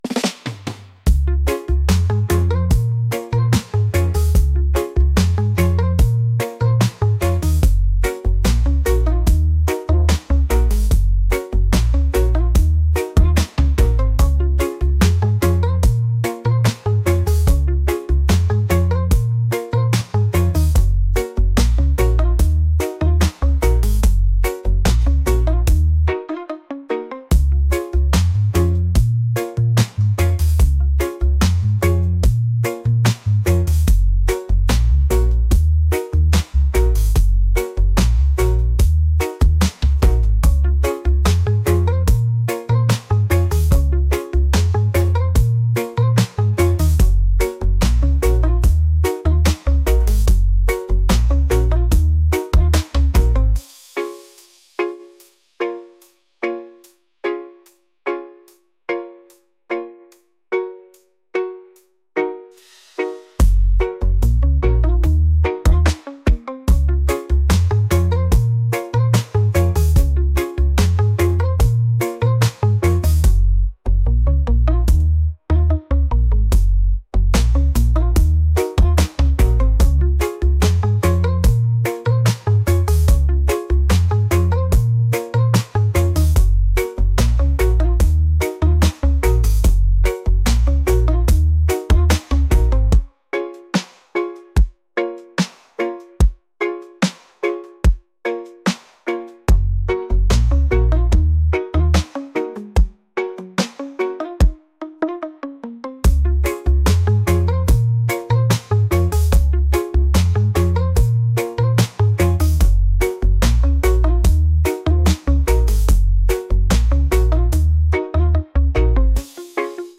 reggae | soul & rnb | pop